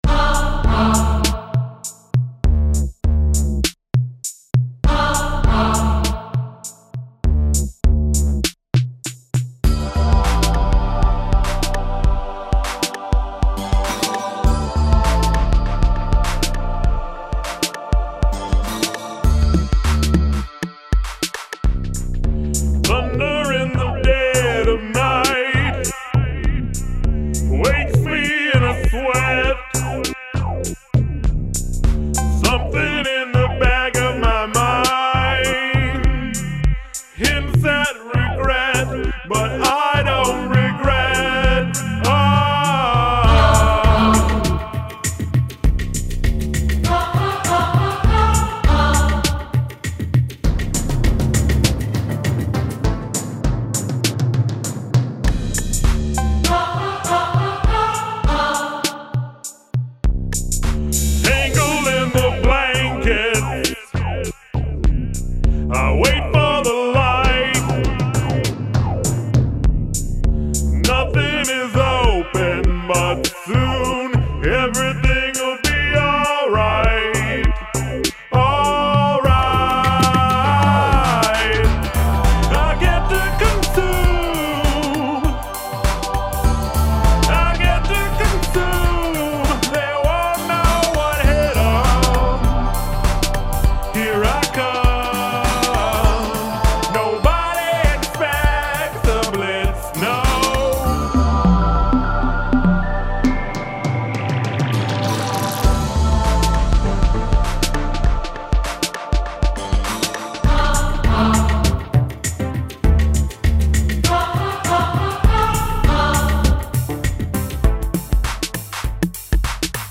Use of choral voices
sampled choral vocals? It works.
Patch fufills challenge, cool weird song.
Vocals are very well done.